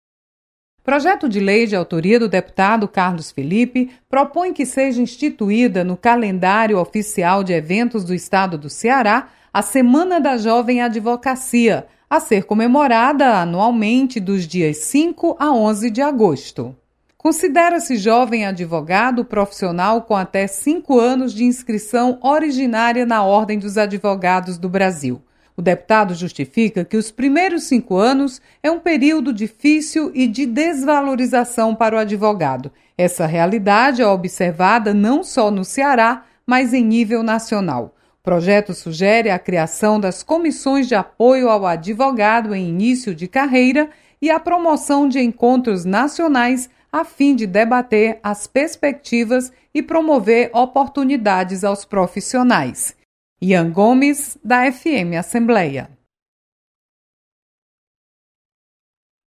Deputado propõe valorização para advogados iniciantes. Repórter